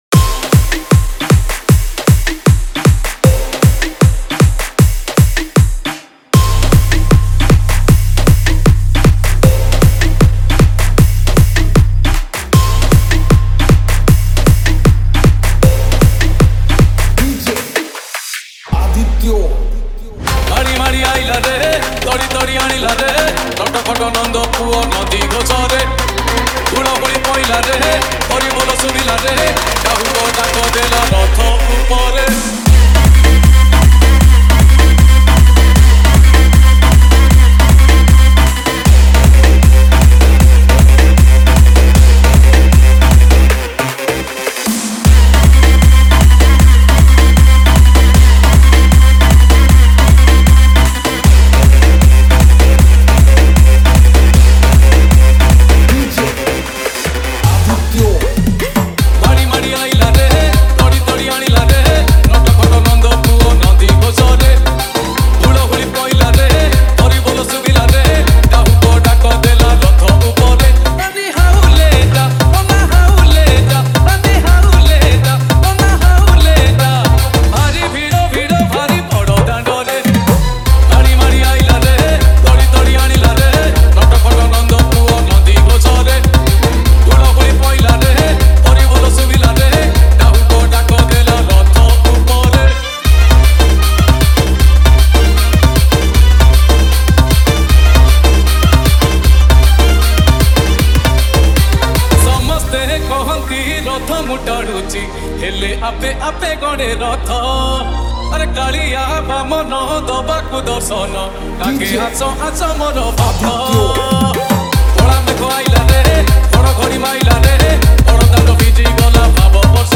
Bhajan Dj Song Collection 2025 Songs Download